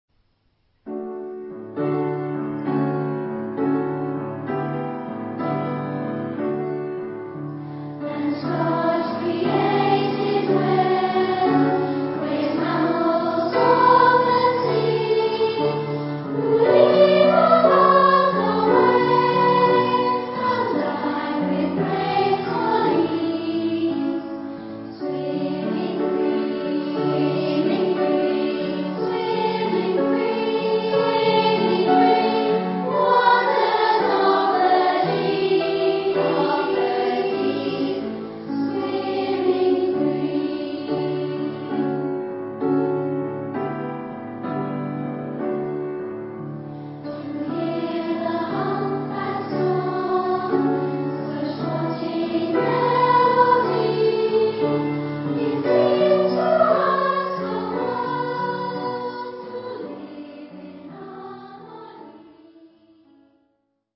Genre-Style-Forme : Profane ; Musique à l'usage des écoles
Caractère de la pièce : mélodieux ; obsédant ; modéré ; expressif ; coulant
Type de choeur : SA OU unison  (1 voix unisson OU unisson + déchant )
Instrumentation : Piano  (1 partie(s) instrumentale(s))
Instruments : Piano (1)
Tonalité : ré majeur